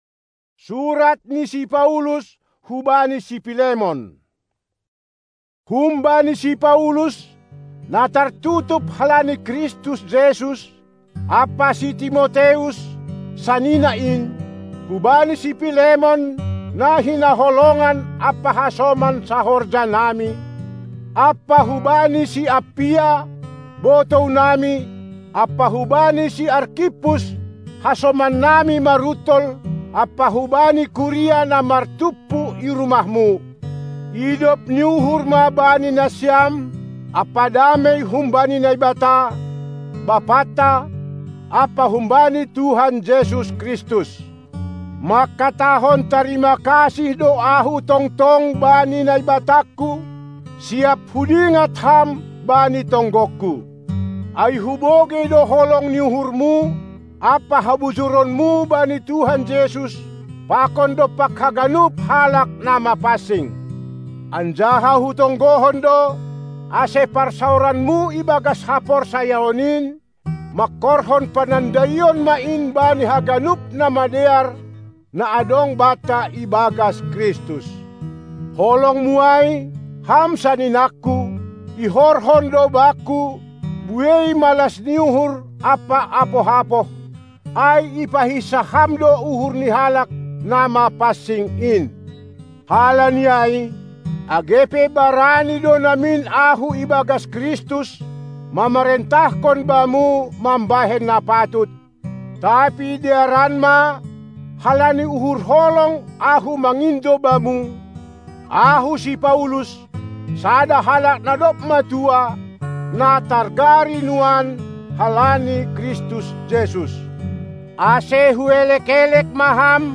Drama